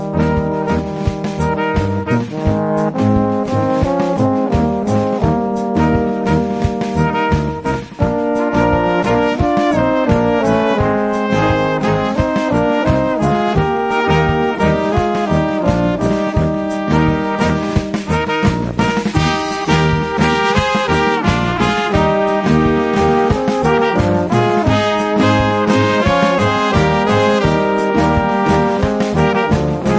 Gattung: für kleine Besetzung
Besetzung: Kleine Blasmusik-Besetzung